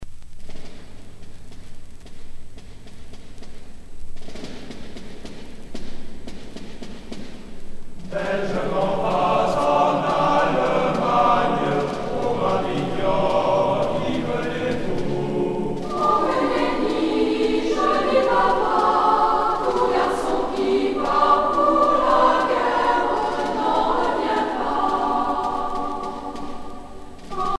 Franco-Allemande de Paris (chorale)
Pièce musicale éditée